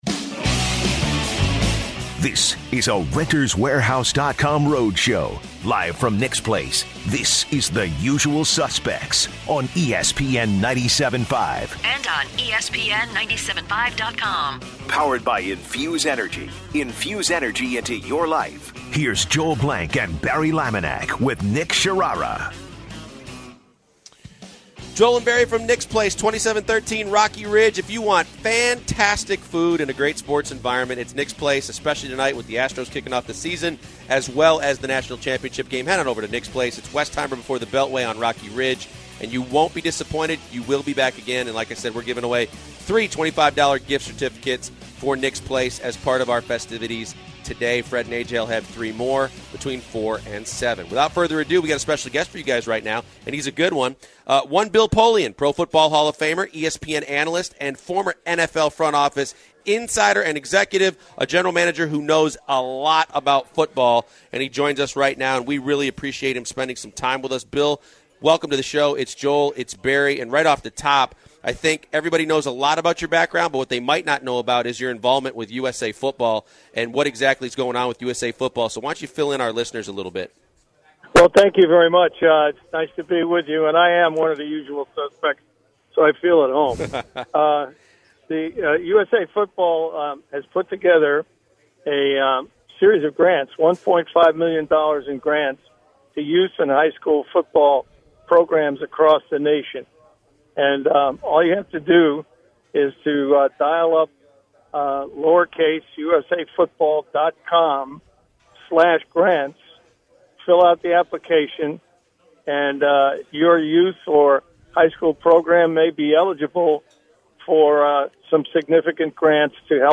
Bill Polian Interview
Pro Football Hall of fame GM Bill Polian calls in to talk about the Tony Romo debacle, the NFL draft and who the best QB prospects are and the Brock Oswiler trade.